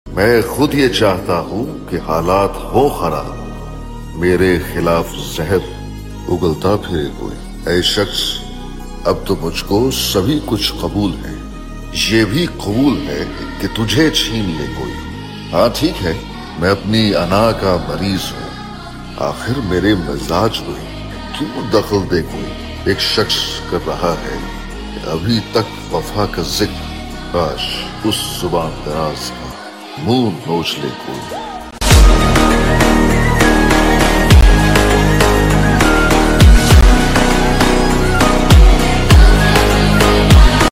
Repost || ❤‍🔥Motivational Speach 🔥 sound effects free download